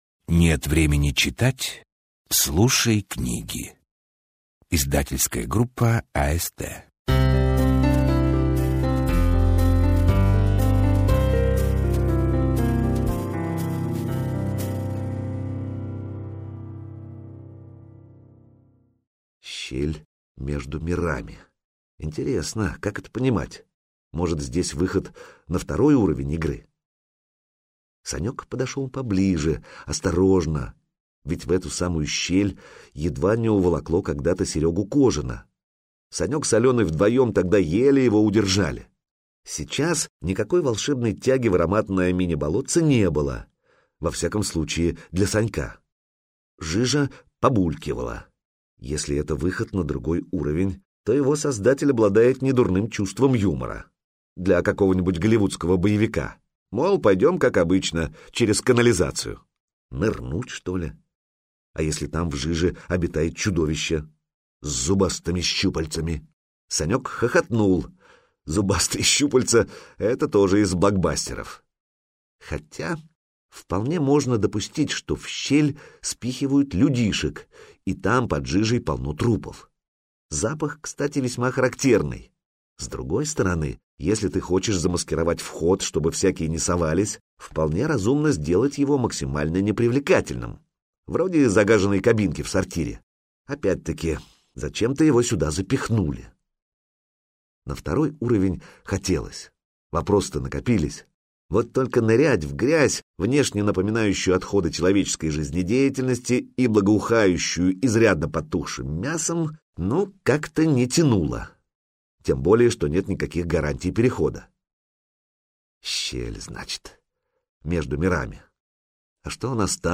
Аудиокнига Игры викингов | Библиотека аудиокниг